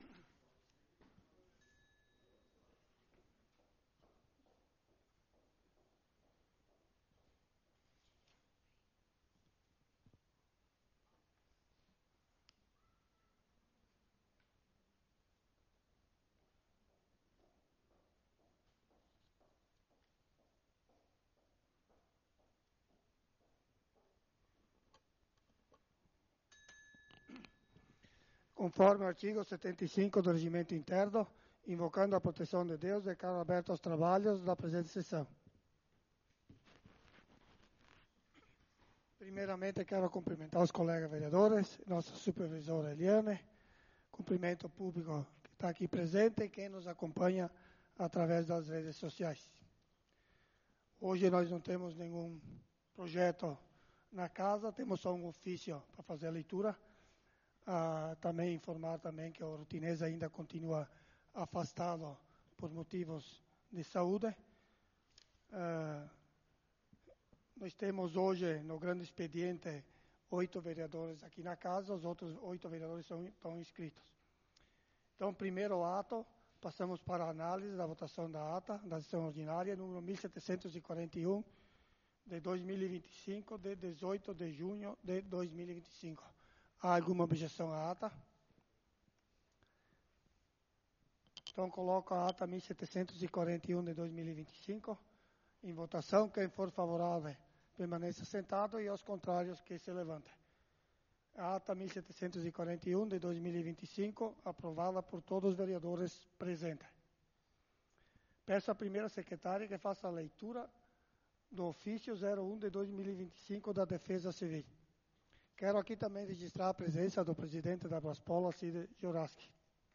Sessão Ordinária do dia 25/06/2025